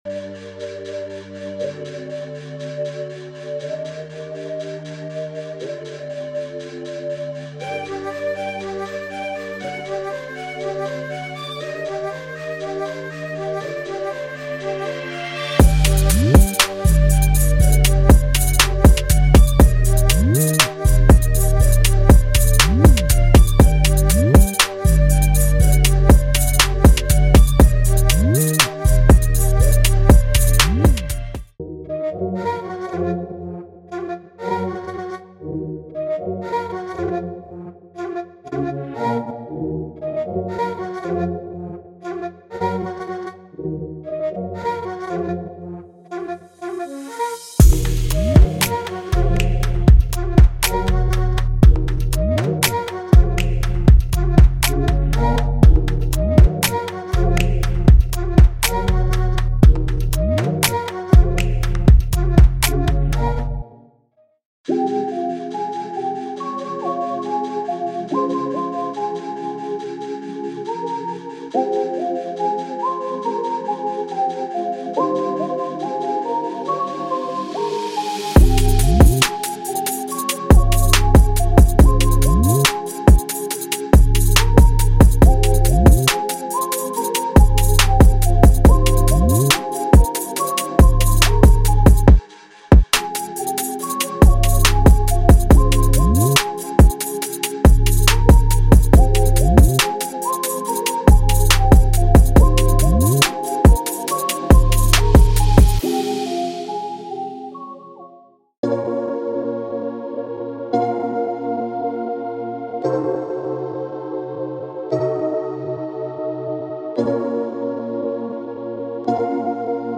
您将在其中找到100个和弦进行曲，100个对应的旋律和200多个鼓，贝斯和打击乐循环。
鼓将作为16个声音的完整鼓架来。
这些旋律表达，和弦进行和鼓架非常适合想要制作现代陷阱，嘻哈，Cloud Rap等的任何制作人！